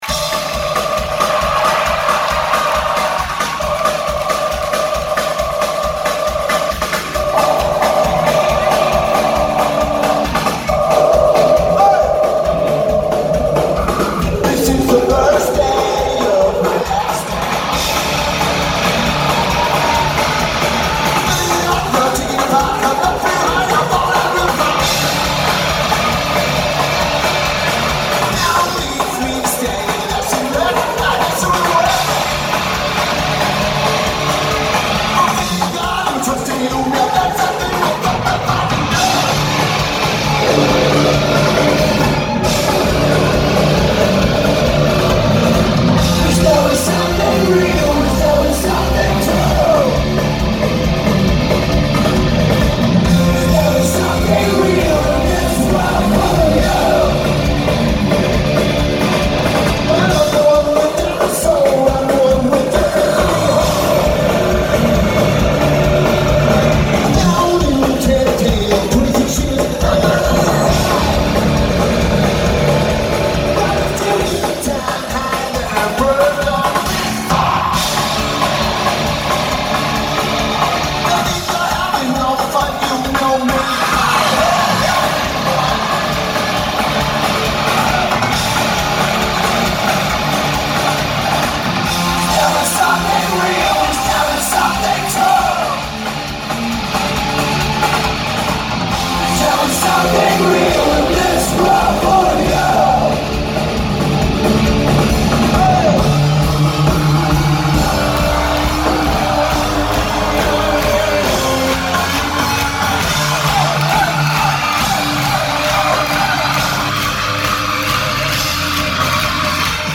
L'Olympia
Paris France
Lineage: Audio - AUD (Samsung Galaxy S9)
Notes: Recorded video from behind the soundboard.